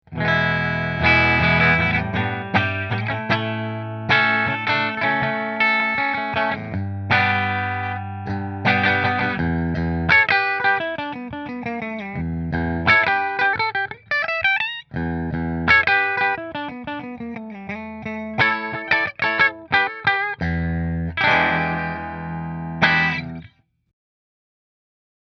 • Mahogany Body with Maple Top
• Seymour Duncan Pickups
Rahan Guitars RP Single Cutaway Figured Maple Bridge Through Fender